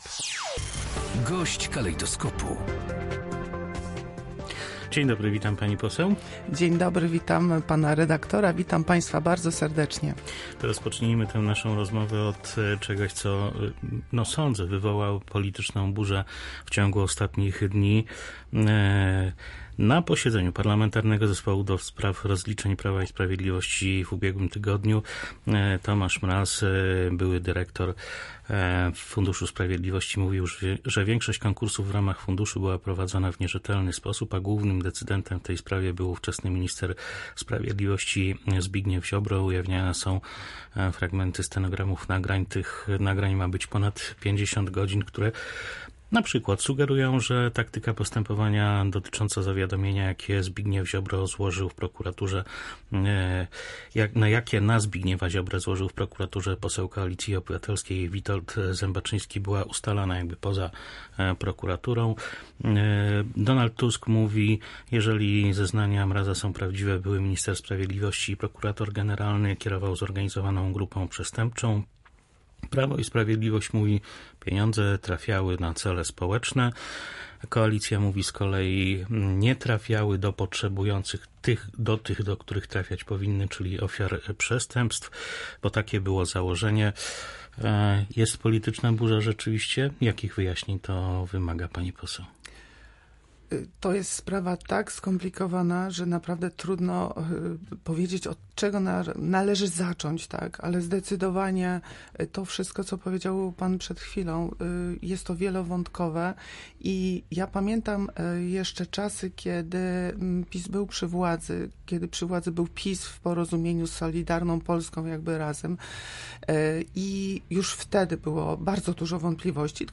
GOŚĆ DNIA. Posłanka Elżbieta Burkiewicz